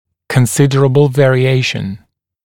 kən'sɪdərəbl ˌveərɪ'eɪʃn]